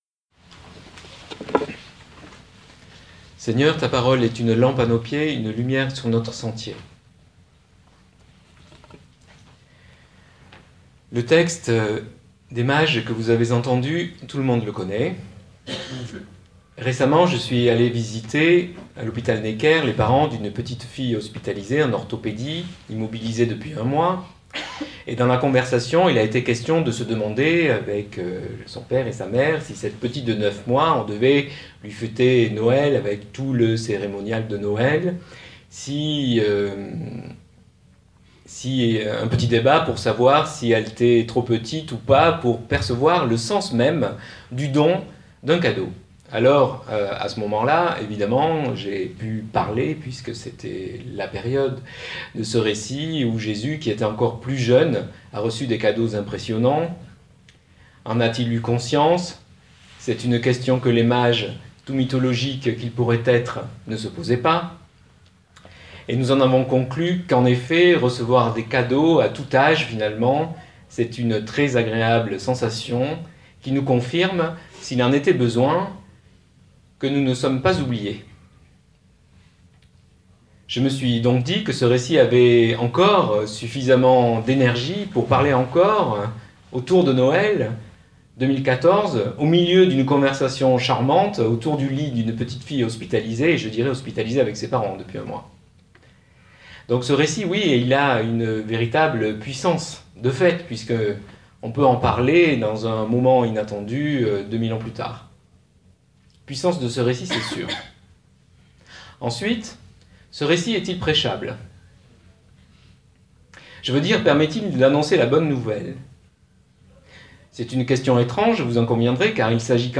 LE PIEGE RELIGIEUX, prédication du 4 janvier 2015-TEXTE ET AUDIO